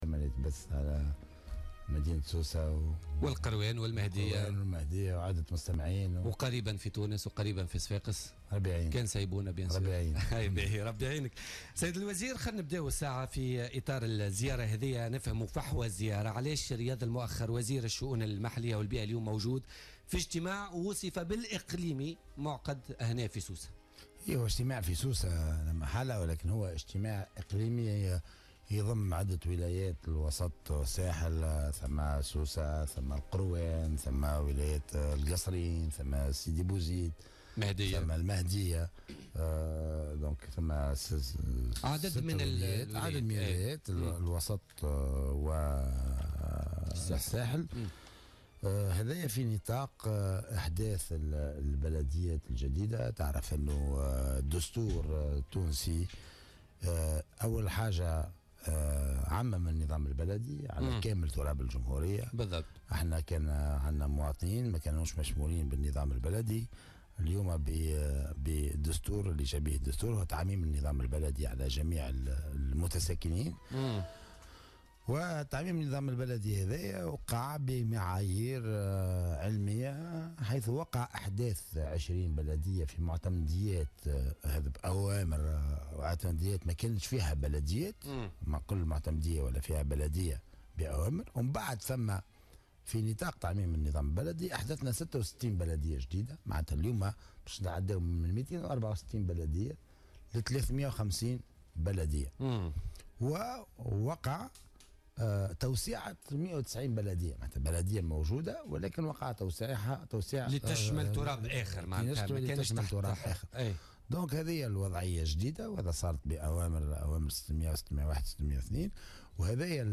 أكد وزير الشؤون المحلية و البيئة رياض المؤخر ضيف بوليتيكا اليوم الخميس 24 أكتوبر 2016 أن هناك اتجاه لتعميم البلديات على مختلف أنحاء الجمهورية موضحا أن لوزارة في نقاش متقدم مع ممولين لتمويل هذه البلديات الجديدة .